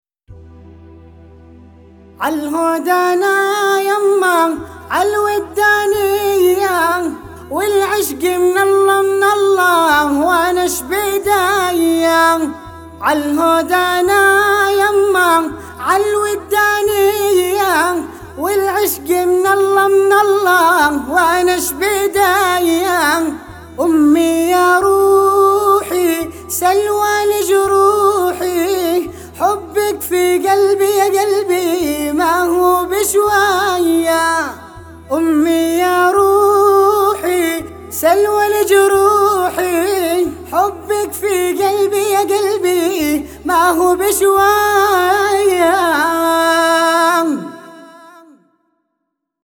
صبا